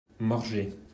French pronunciation (Valdôtain: Mordzé) is a town and comune in the Aosta Valley region of north-western Italy.
Fr-Morgex.mp3